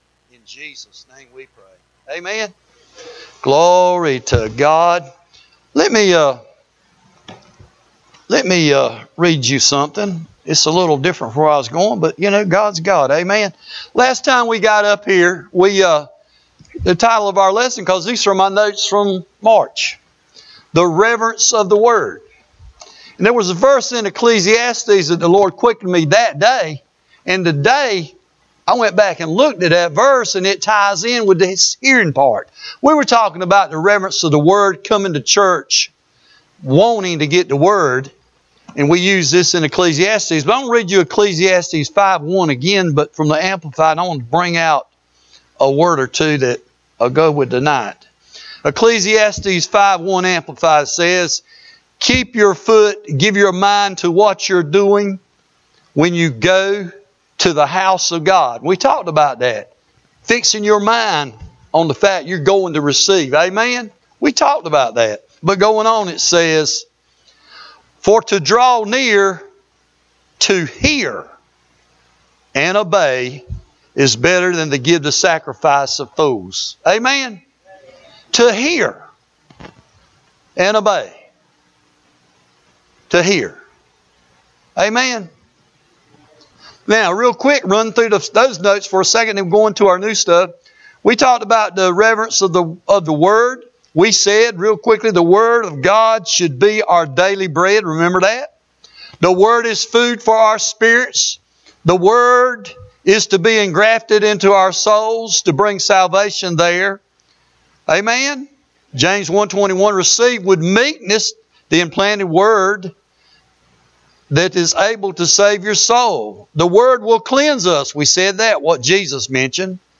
Sermons - Word of Life Fellowship Church